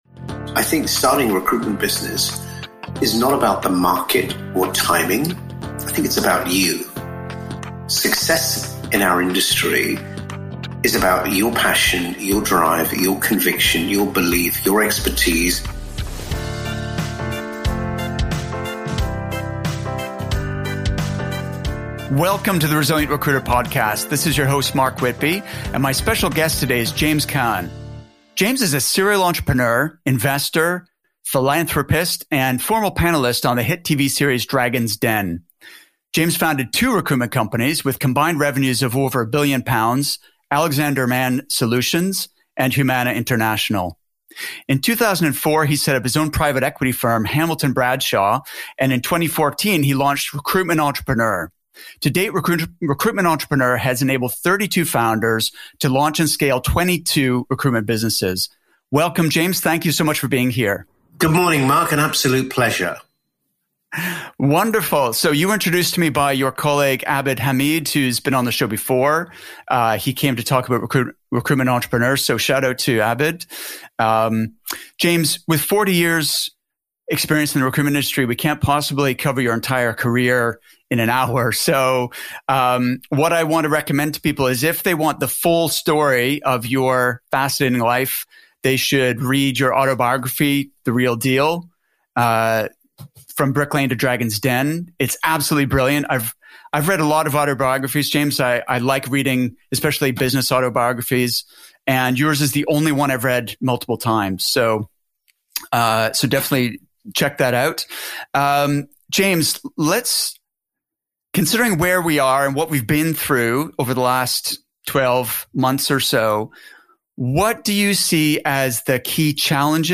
In this interview, James and I discussed the challenges and opportunities for recruiters in a post-pandemic environment, and what it takes to achieve extraordinary success in our industry.